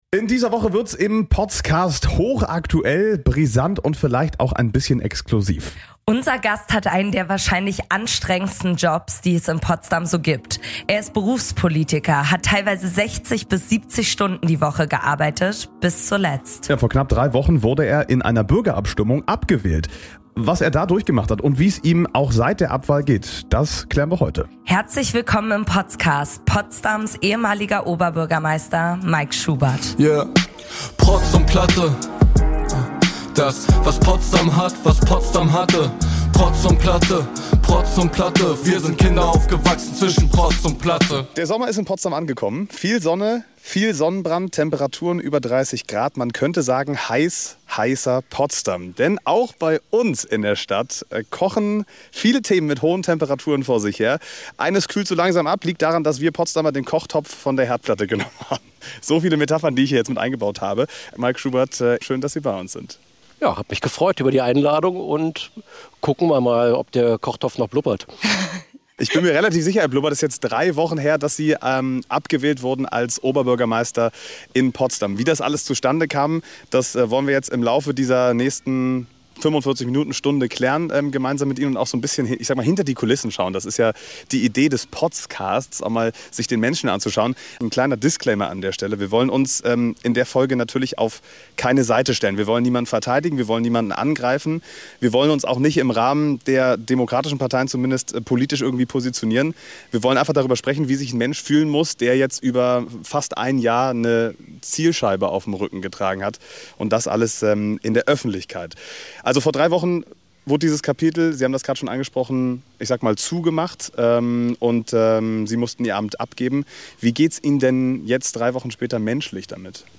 Mike Schubert war seit 2018 Oberbürgermeister in Potsdam. Musste nach mächtigem, politischen Gegenwind und einer verlorenen Bürgerabstimmung seinen Hut nehmen. Wir haben exklusiv mit ihm über die letzten Monate gesprochen: Was haben die letzten Monate mit ihm als Mensch gemacht?